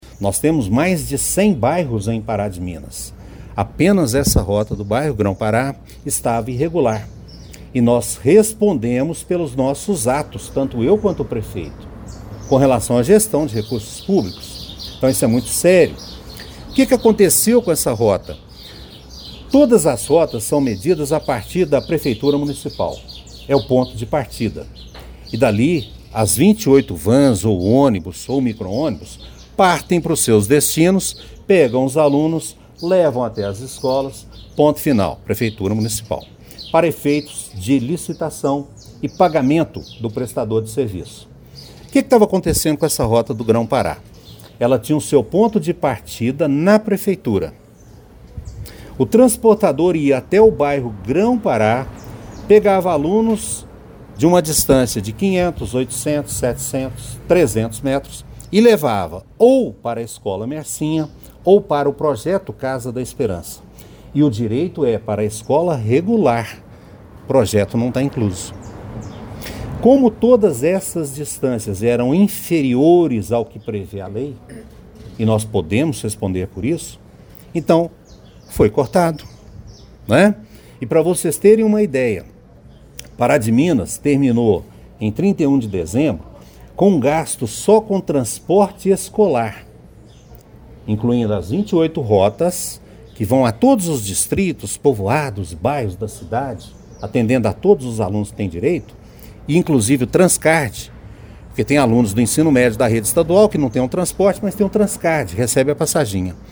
Durante coletiva de imprensa, o secretário de Educação, Marcos Aurélio dos Santos, destacou que o transporte escolar é um direito garantido pela Constituição Federal e por legislações específicas que regulam o setor.